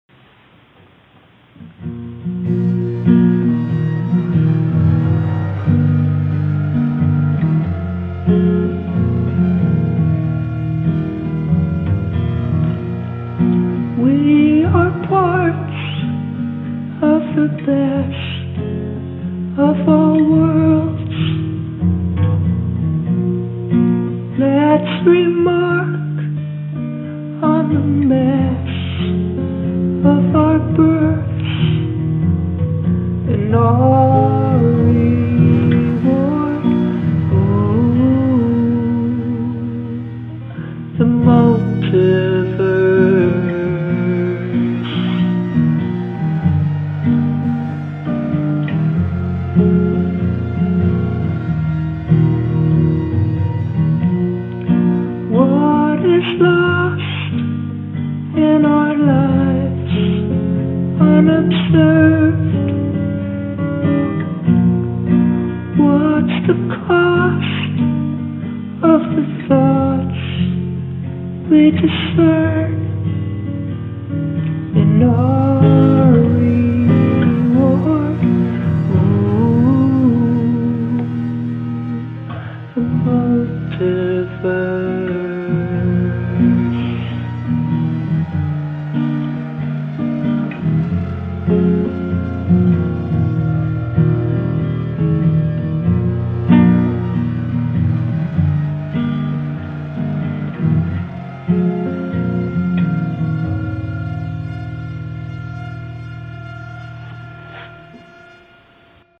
verse: D, Bm, Am, G (x2)
C, G, D, G, Gm
felt like knocking a song out tonight. was thinking about alternate worlds as a starting place. i recorded it on my phone quietly in my room, then recorded some distorted synths on top of it in logic.